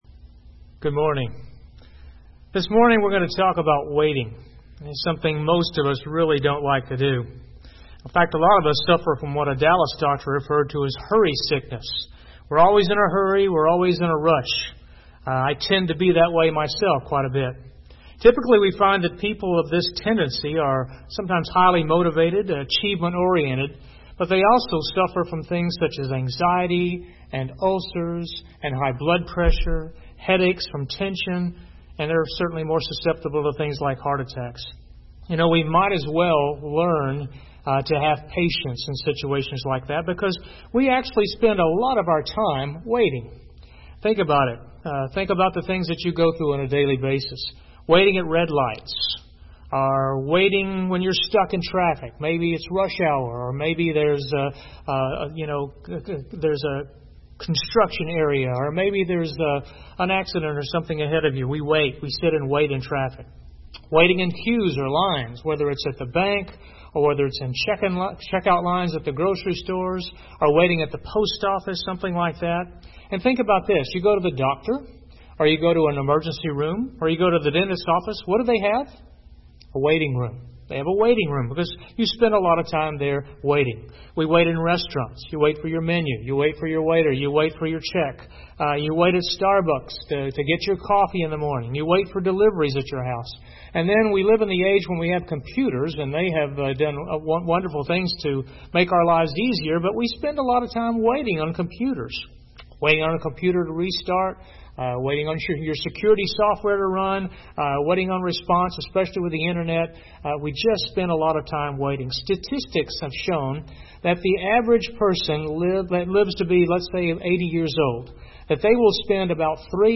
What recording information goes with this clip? Sunday Morning Worship Services - Grace Bible Church of Fort Worth